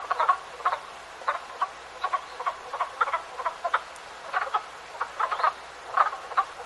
دانلود صدای قورباغه ماده در شالیزار از ساعد نیوز با لینک مستقیم و کیفیت بالا
جلوه های صوتی